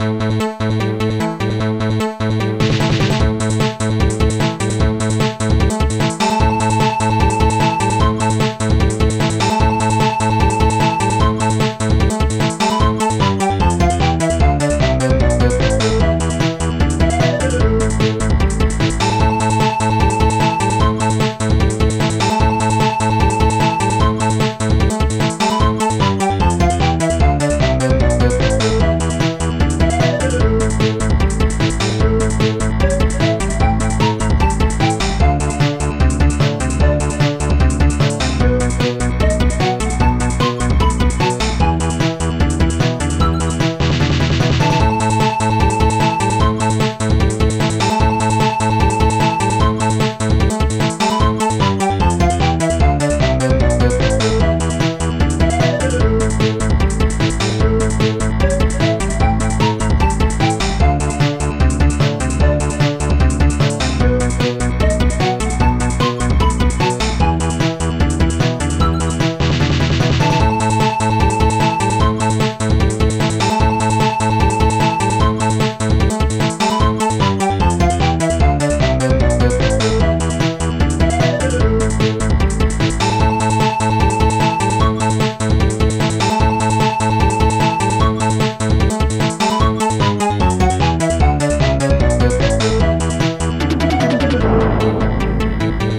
Protracker Module  |  1987-04-22  |  57KB  |  2 channels  |  44,100 sample rate  |  1 minute, 36 seconds
Protracker and family
st-02:bassdrum5
st-02:snare8
st-02:hihat4